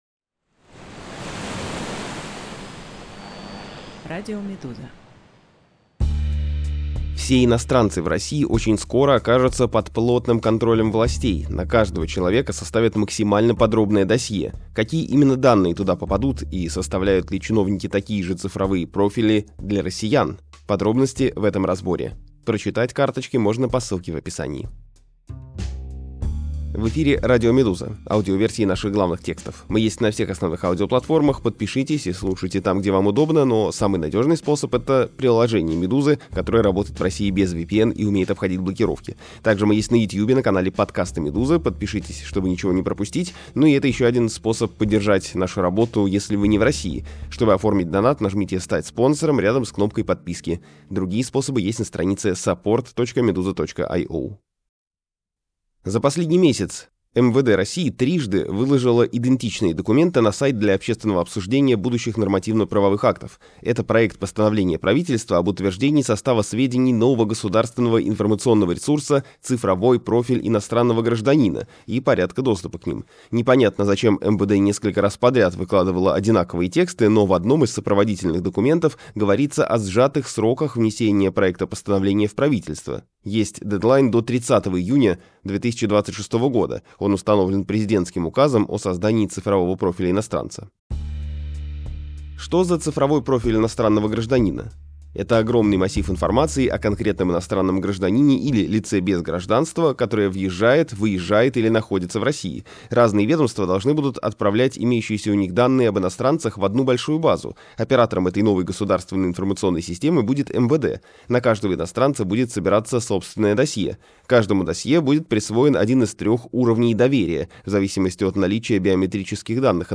Аудиоверсия разбора.